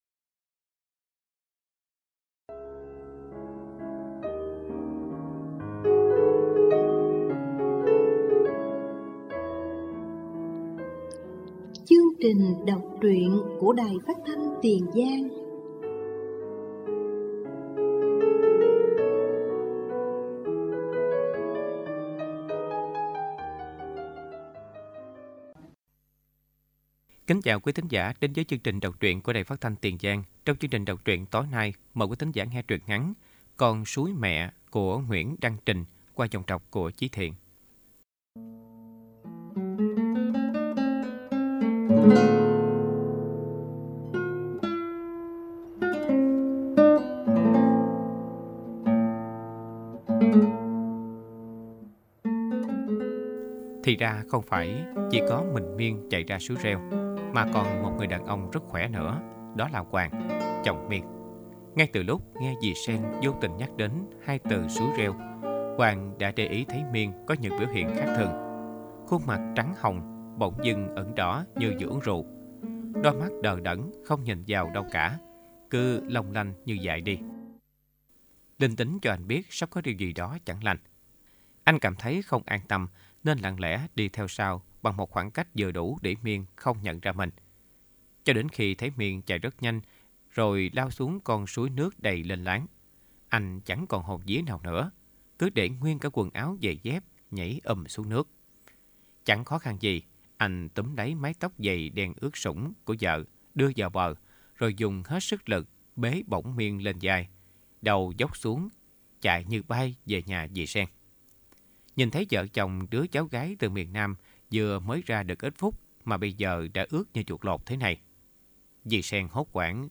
Đọc truyện “Con suối mẹ”